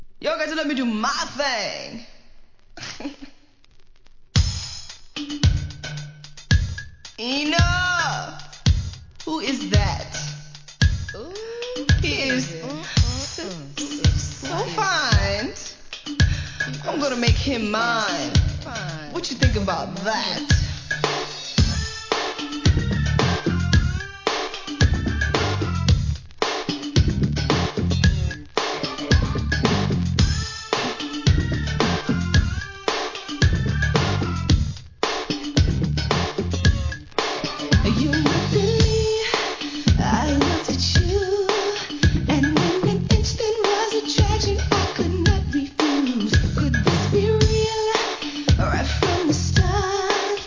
HIP HOP/R&B
UPテンポなNEW JACK SWING調の跳ね系でかためられた1991年 ALBUM!!